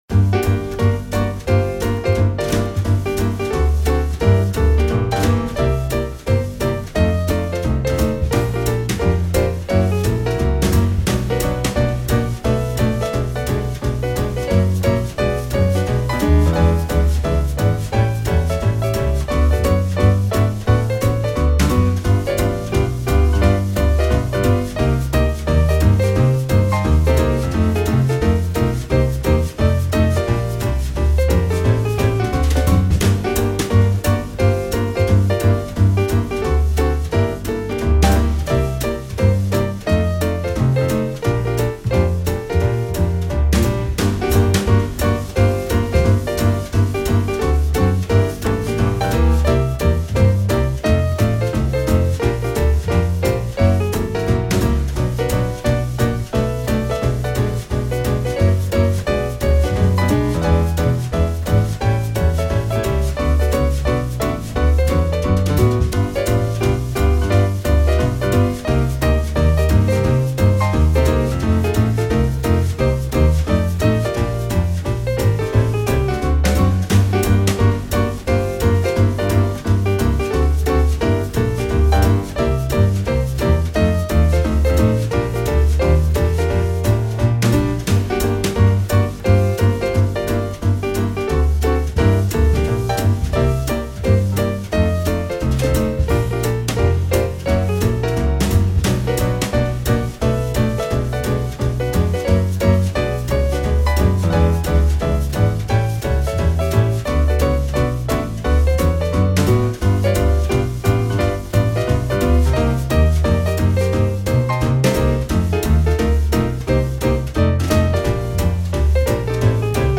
活动BGM